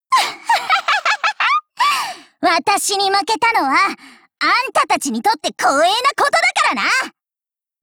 贡献 ） 协议：Copyright，人物： 碧蓝航线:凶猛语音 您不可以覆盖此文件。